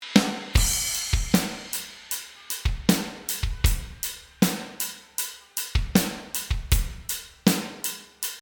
Aside: Since the kick drum is a part of the reverb as well, I’ve filtered the low-end out of the reverb up to about 150-200 Hz to reduce the boomy clutter that can result from a reverbed kick drum.
Here’s the kit with the hall reverb.
drumshall.mp3